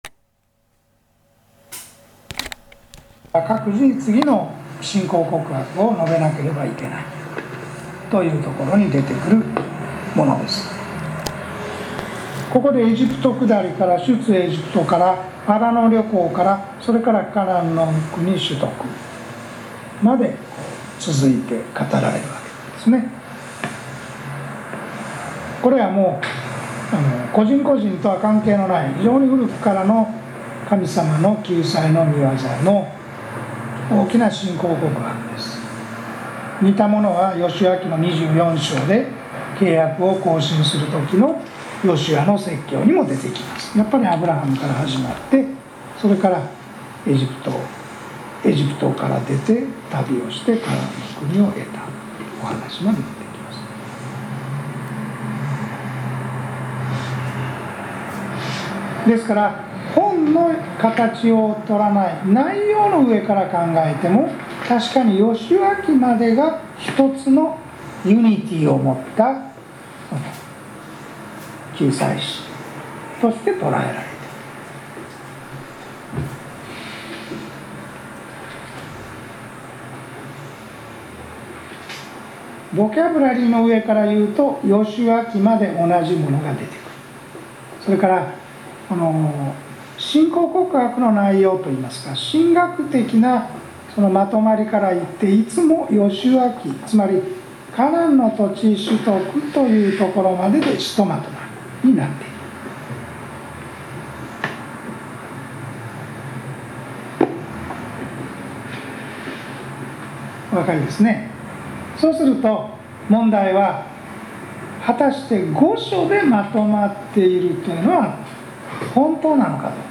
（カセットテープの関係で、途中音声の一部が飛んでいる。パソコンのスピーカーではなく、低音の出るスピーカー、イヤホン、ヘッドホンを使うと聞きやすい。）